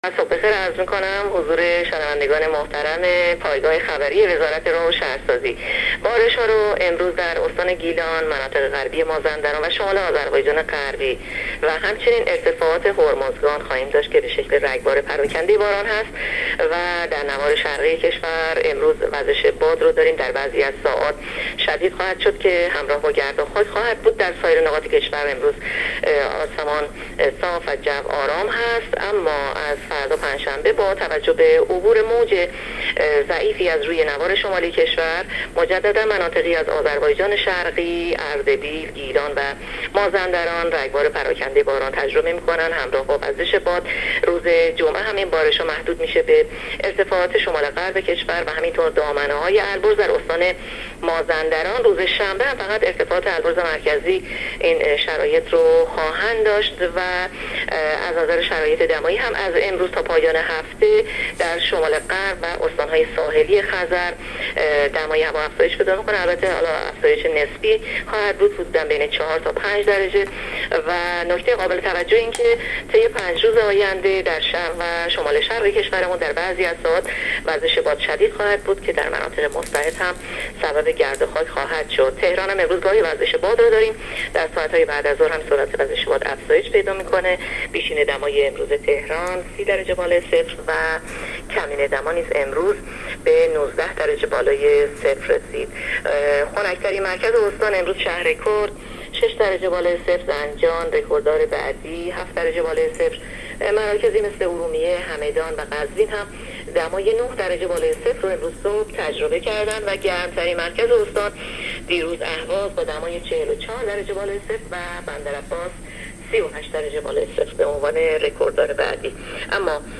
گزارش رادیو اینترنتی پایگاه‌ خبری از آخرین وضعیت آب‌وهوای ۲۹ شهریور؛